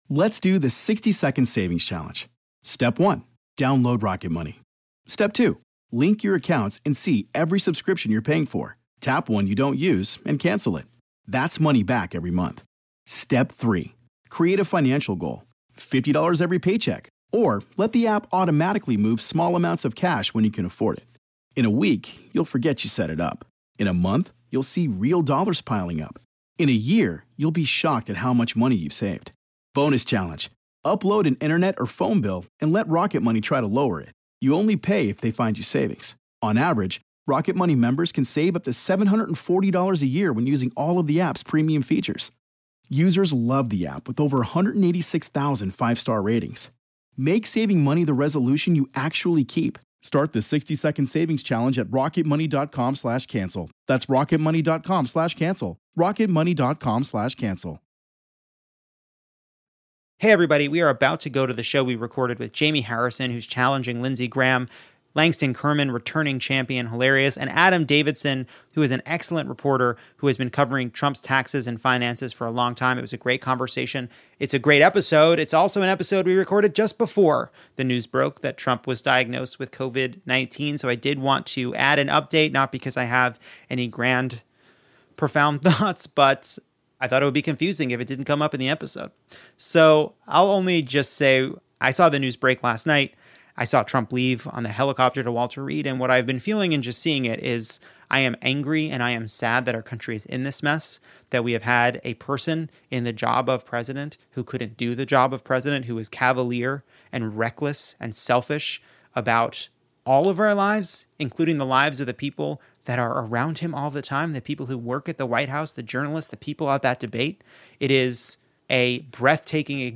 Returning champion Langston Kerman joins for the top of the show, then I talk to Jaime Harrison about his race against Lindsey Graham and journalist Adam Davidson about Trump's taxes, money laundering, and why it's important to stop corruption before it's too late.